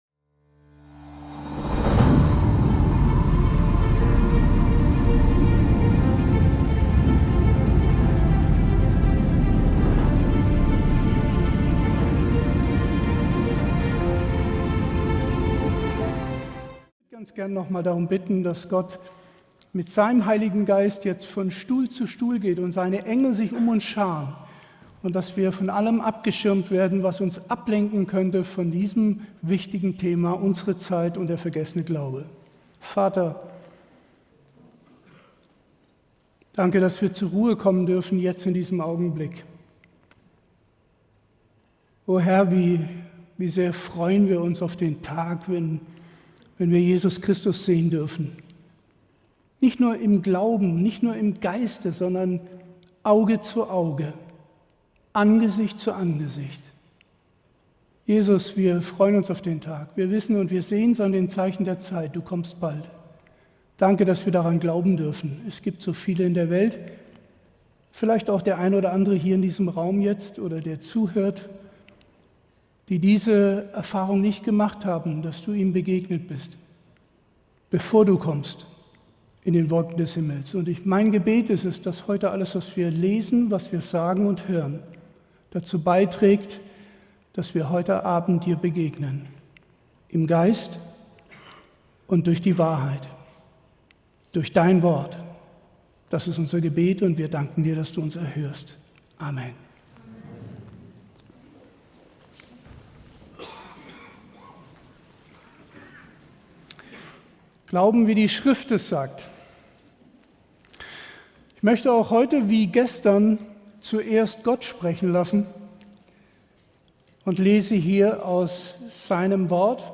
Im faszinierenden Vortrag wird der Glaube in der heutigen Zeit beleuchtet. Die zentrale Frage ist: Wird Jesus bei seiner Rückkehr Glauben auf Erden finden?